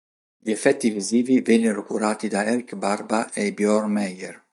Hyphenated as ef‧fèt‧ti Pronounced as (IPA) /efˈfɛt.ti/